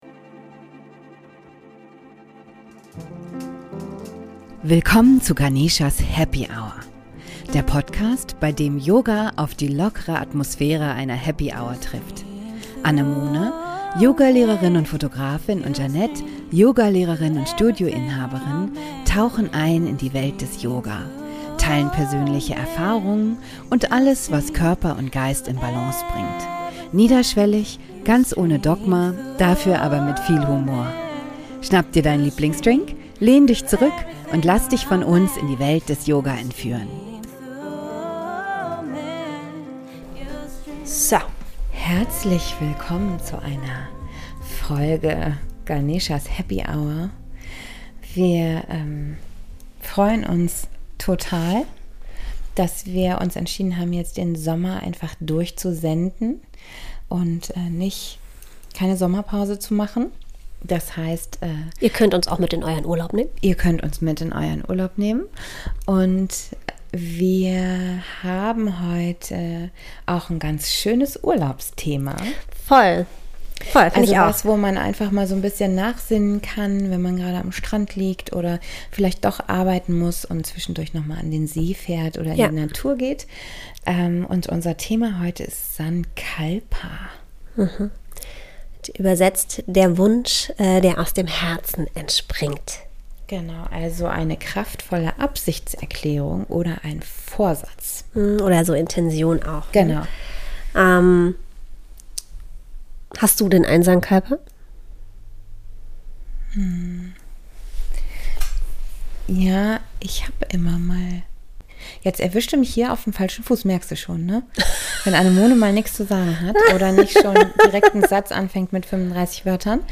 Als Goodie zum Durchstarten gibt es am Ende der Folge noch eine Sankalpa-Meditation!